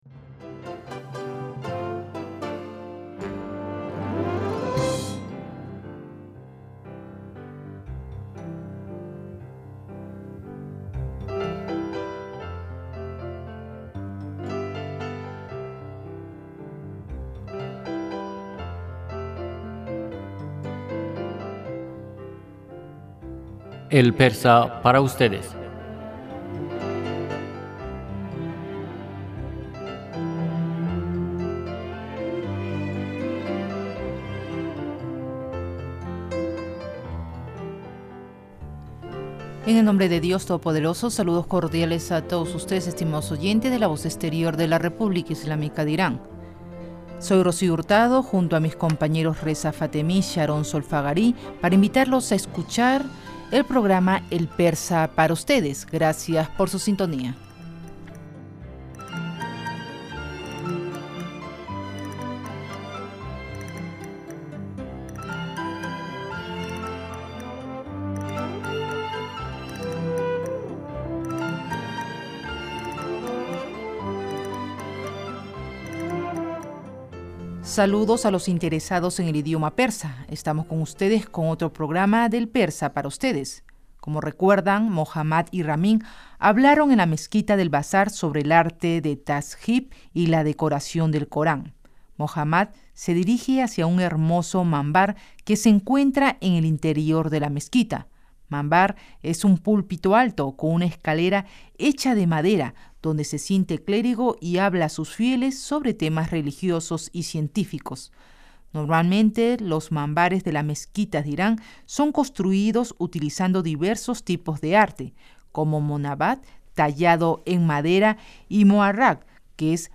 Locutor: Saludos a los interesados en el idioma persa. Estamos con ustedes con otro programa de “El Persa para Ustedes”.